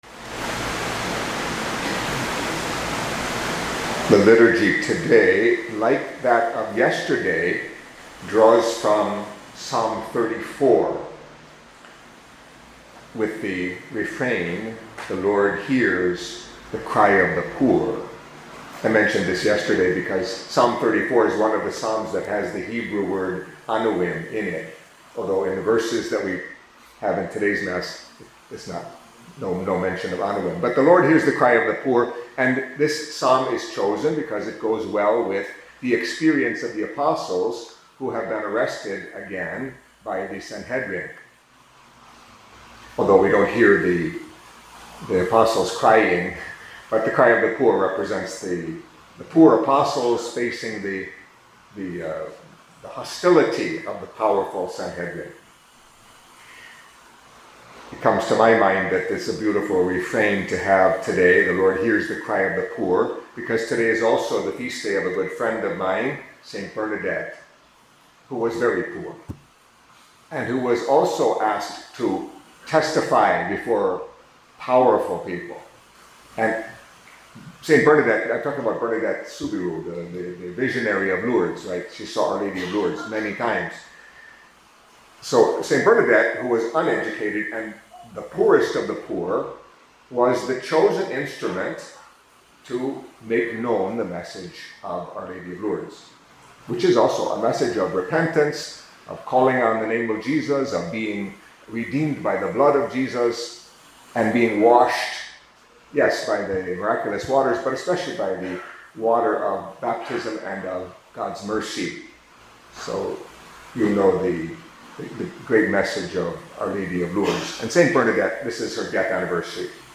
Catholic Mass homily for Thursday in the Second Week of Easter